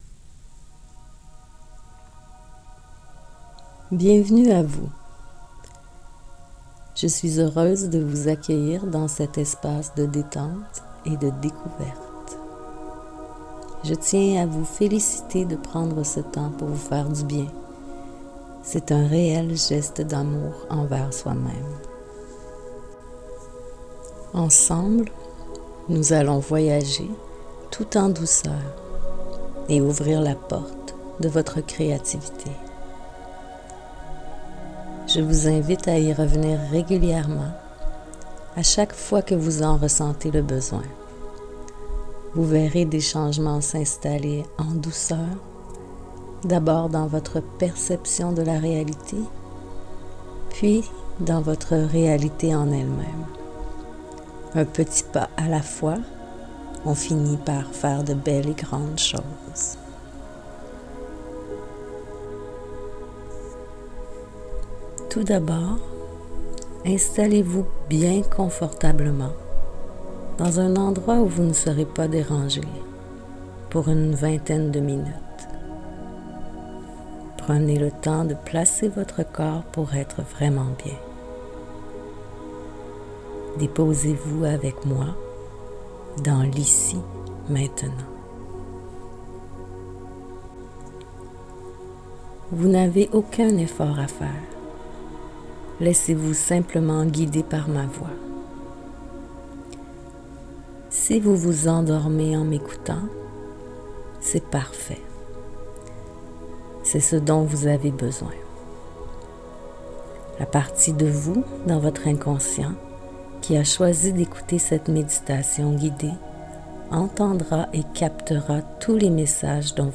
Merci d'avoir demandé votre méditation guidée!